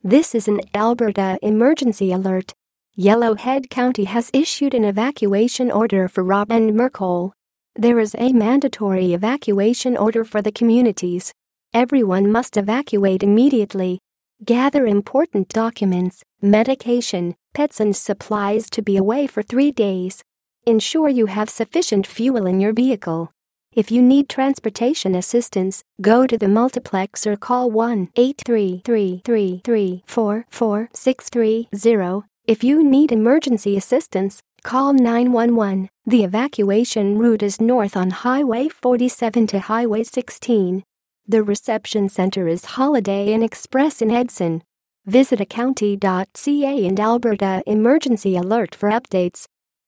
Broadcast Audio